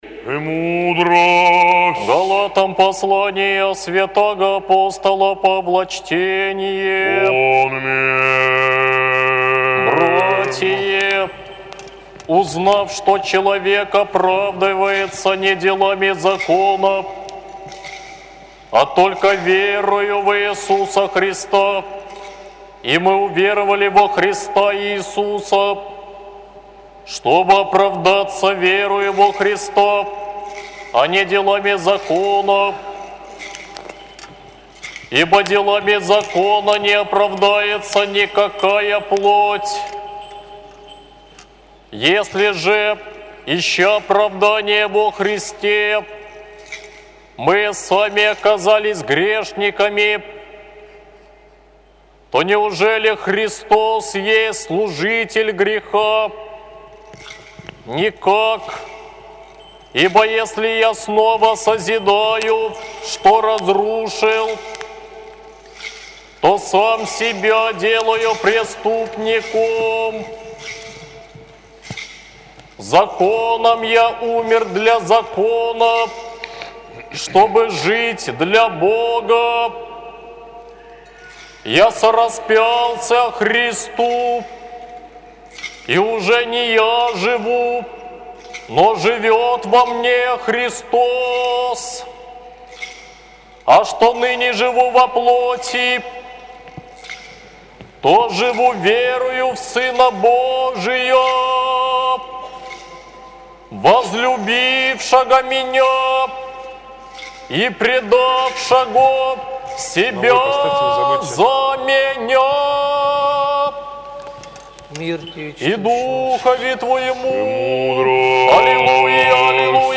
АПОСТОЛЬСКОЕ ЧТЕНИЕ НА ЛИТУРГИИ